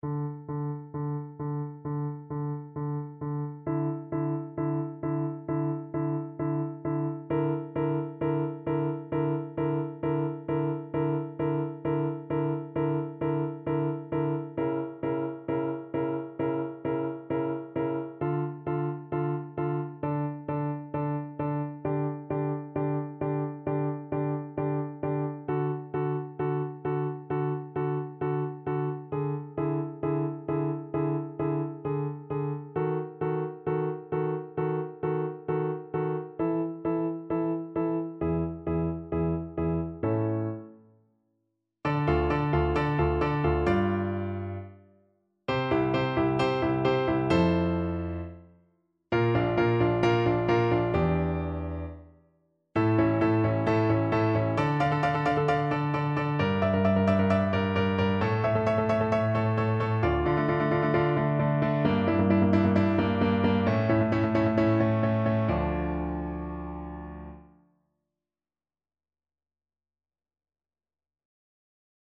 D minor (Sounding Pitch) (View more D minor Music for Oboe )
Allegro non molto =c.132 (View more music marked Allegro)
Classical (View more Classical Oboe Music)